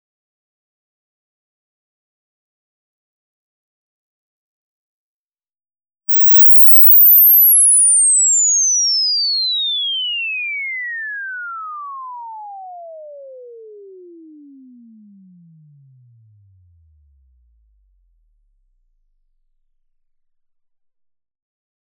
link to the inverse sweep file.